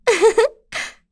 Yuria-Vox-Laugh_b.wav